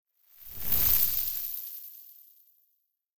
ice_whoosh_09.wav